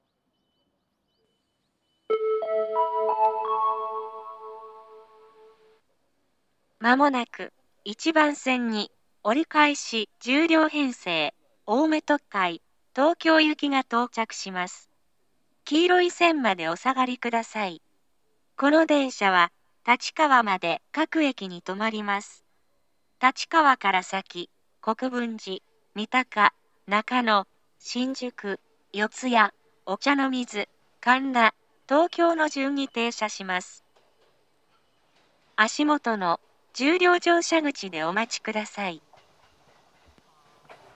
青梅市内の駅です。
駅員による放送が比較的被り易いです。
接近放送
長めに鳴り易くなります。